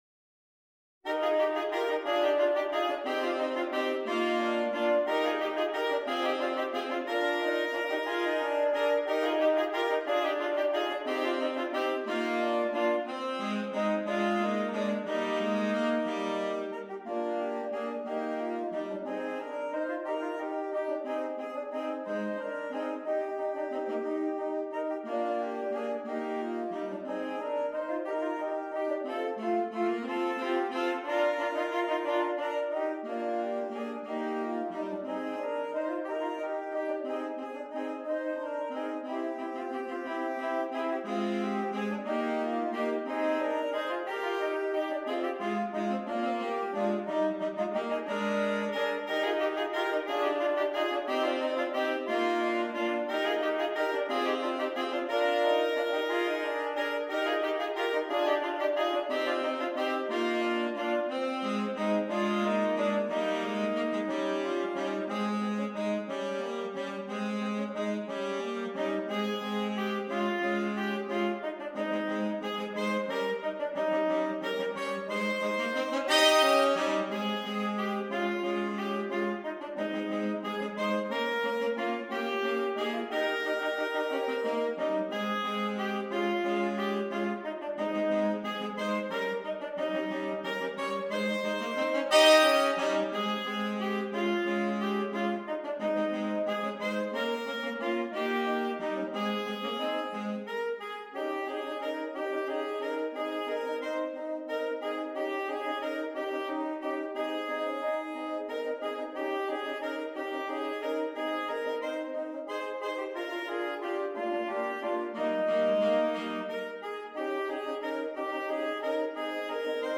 3 Alto Saxophones